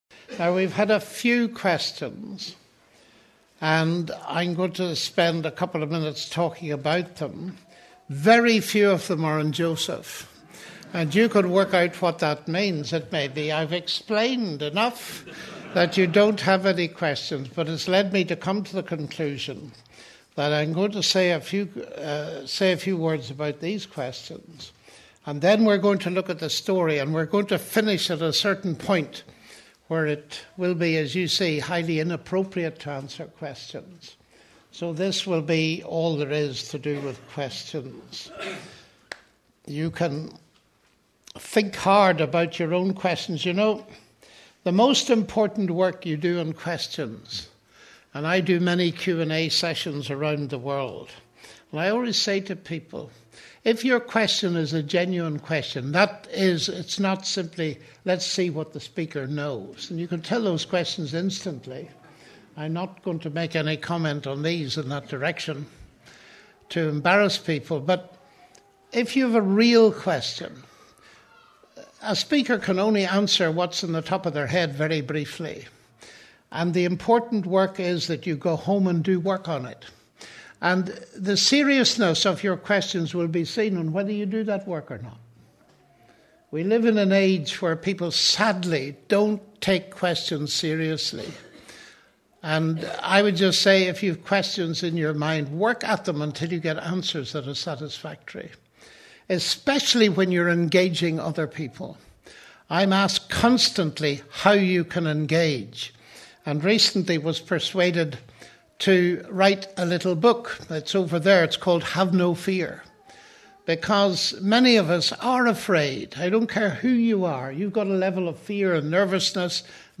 John Lennox answers questions at the Living The Passion Wales conference at Union Bridgend in February 2019